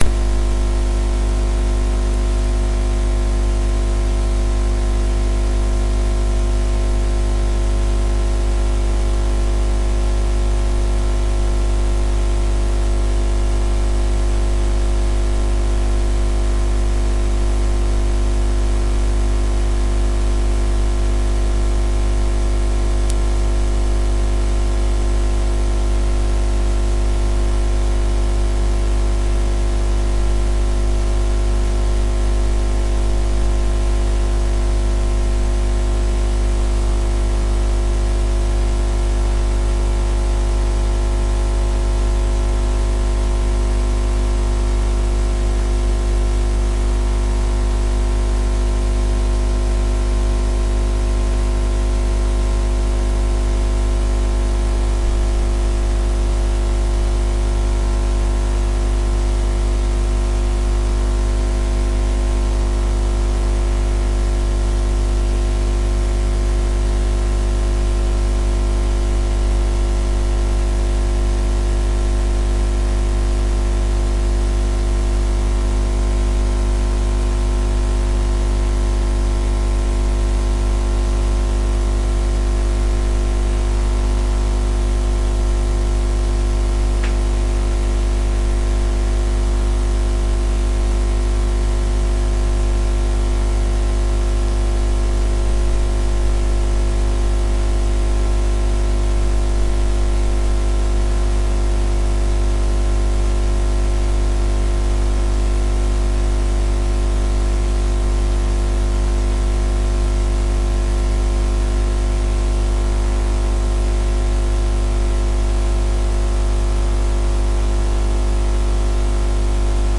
描述：助推器驾驶检查器
Tag: 起动器 ECU 发动机